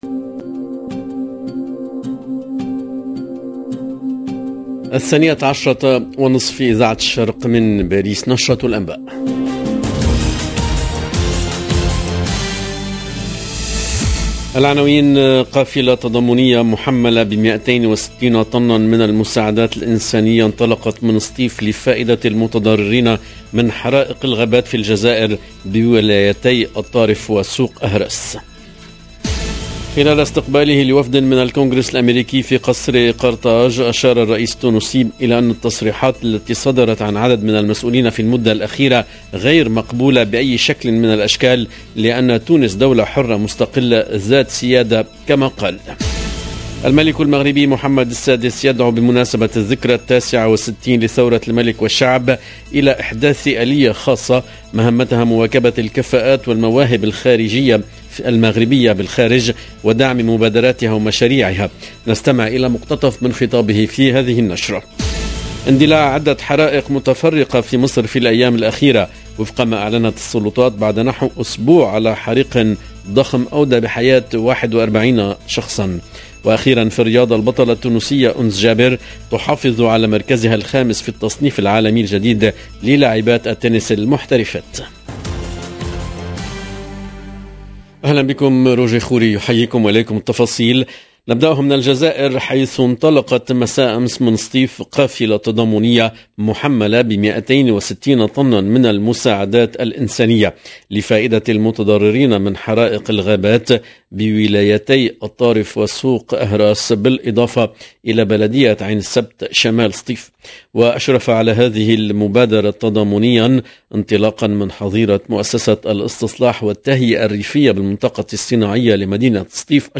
EDITION DU JOURNAL DE 12H30 EN LANGUE ARABE DU 22/8/2022